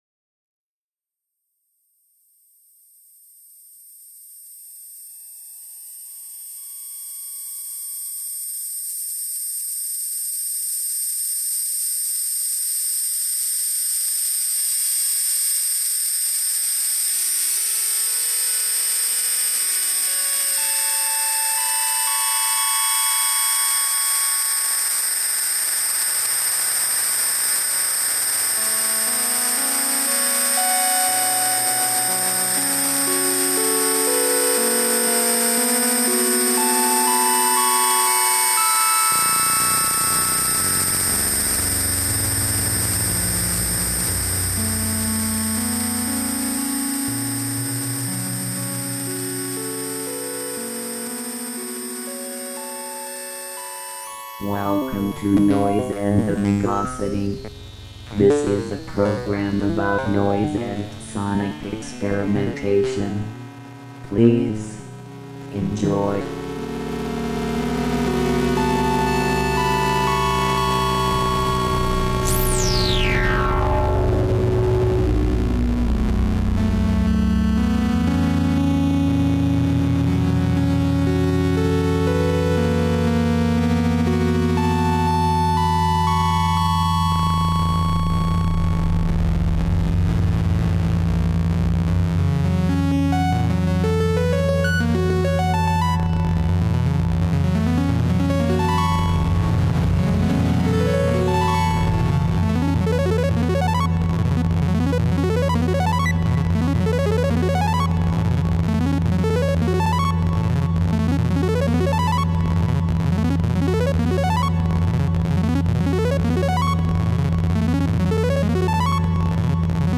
This month on the program we play some improvised music and listen to some Kolkata traffic.